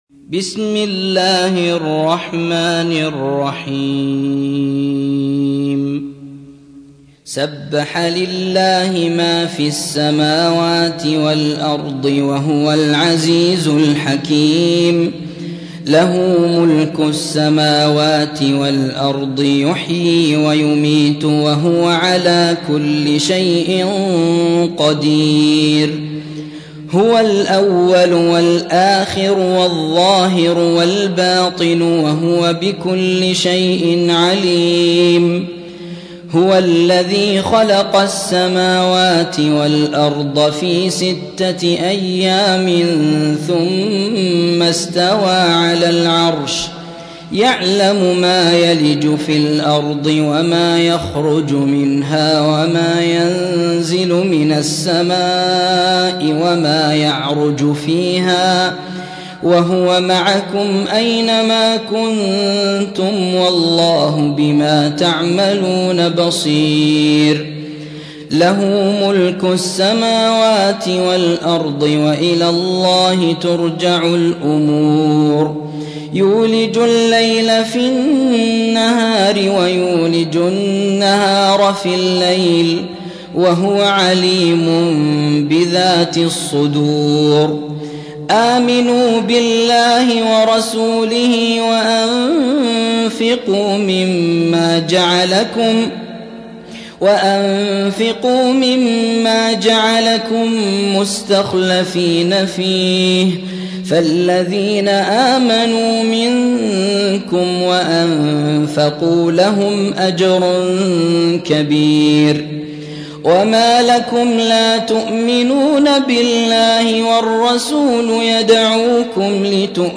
57. سورة الحديد / القارئ
القرآن الكريم